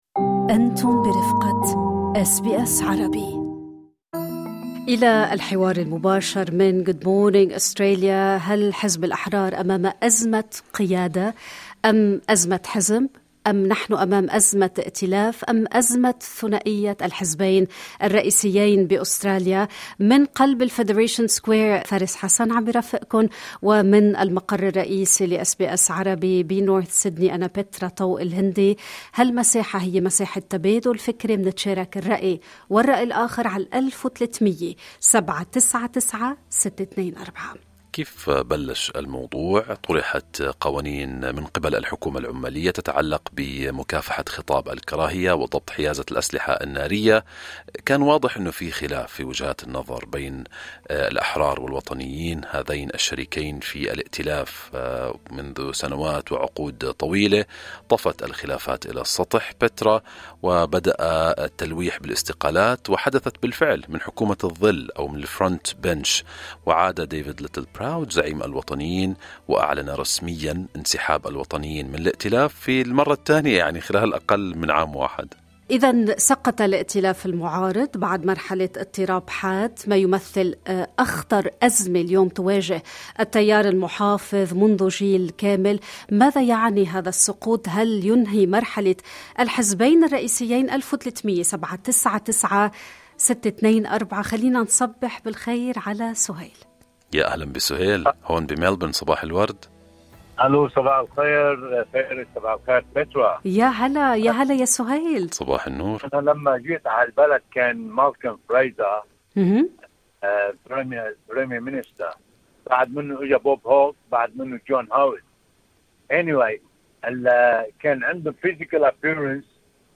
اليكم أراء المستمعين عبر برنامج "صباح الخير استراليا".